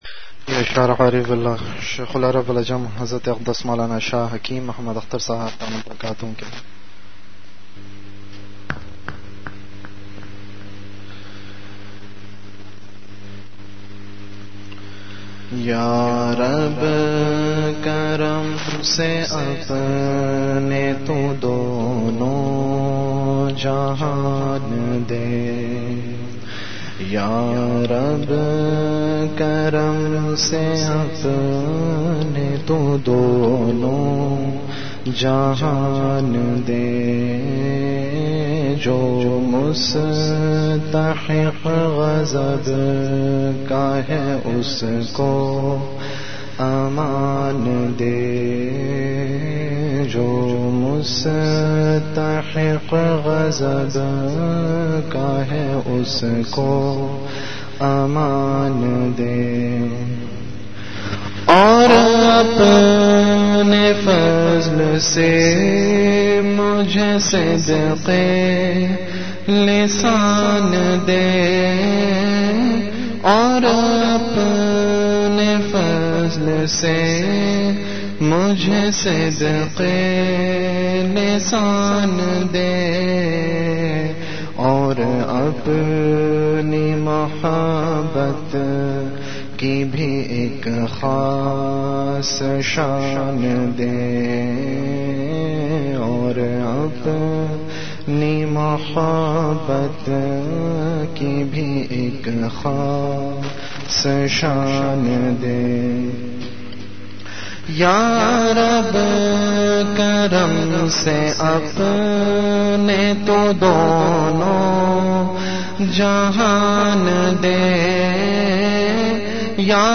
Majlis-e-Zikr